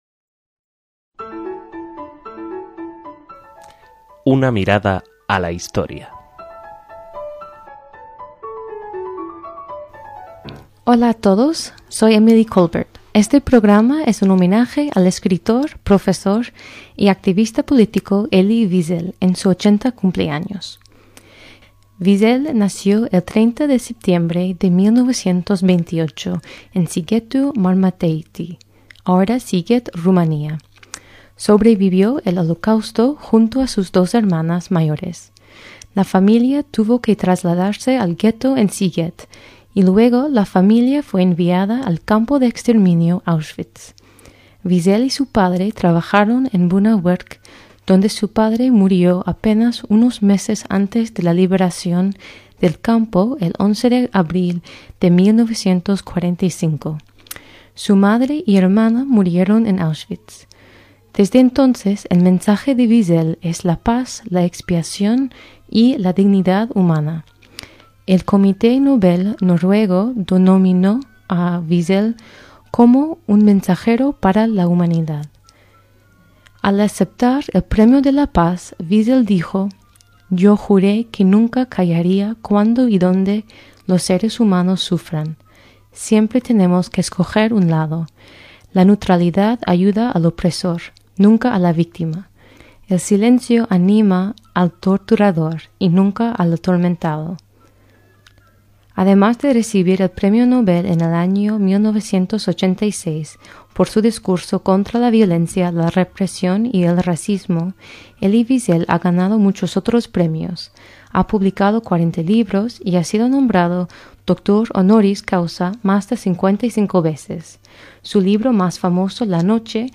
ESPECIAL - Aunque tenía ya 87 años, no podemos entenderlo: Elie Wiesel se ha ido y orfandado a la humanidad de su mensaje contra la indiferencia que le valió el premio Nobel de la Paz en 1986. Sirvan de urgente homenaje estas grabaciones de sendos programas: uno de 2008 glosando su vida cuando cumplía 80 años, y la lectura de un fragmento de su libro más célebre, "La noche".